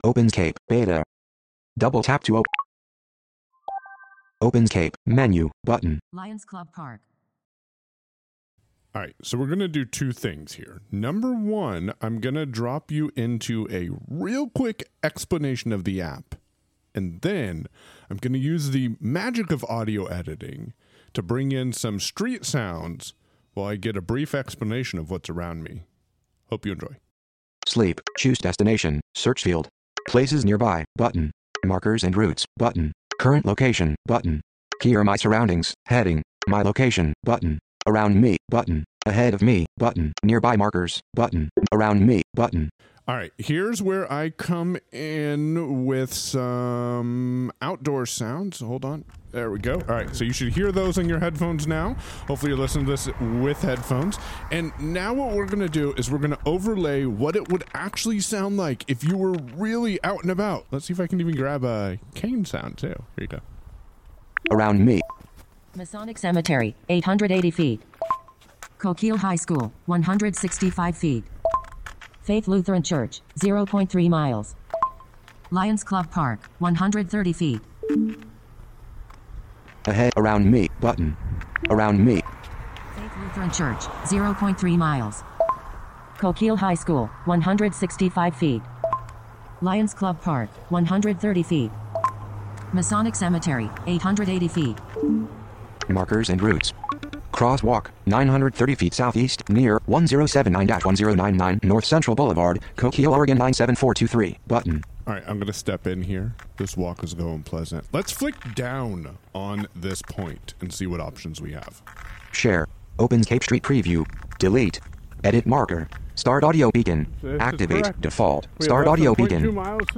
Ok, hears much better audio and you get to keep the environmental sounds that I wanted to give people. Here’s two minutes and 40 seconds demonstrating the. beta app.